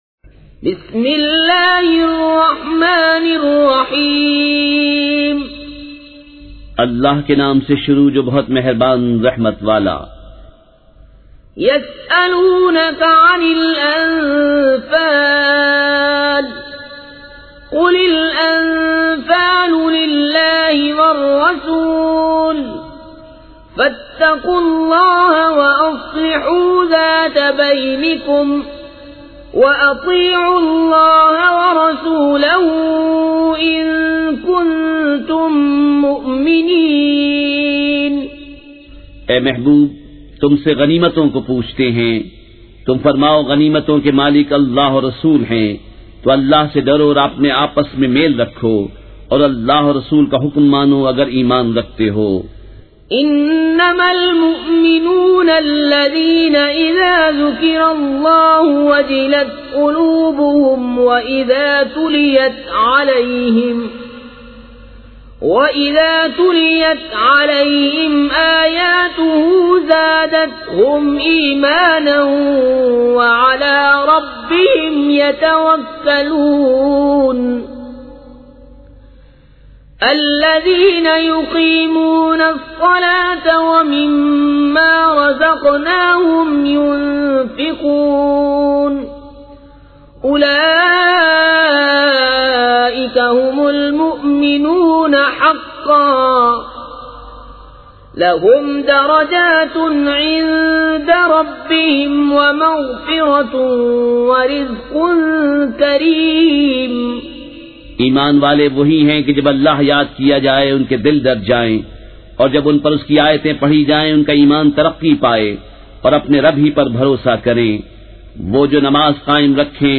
سورۃ الانفال مع ترجمہ کنزالایمان ZiaeTaiba Audio میڈیا کی معلومات نام سورۃ الانفال مع ترجمہ کنزالایمان موضوع تلاوت آواز دیگر زبان عربی کل نتائج 3799 قسم آڈیو ڈاؤن لوڈ MP 3 ڈاؤن لوڈ MP 4 متعلقہ تجویزوآراء
surah-e-anfal-with-urdu-translation.mp3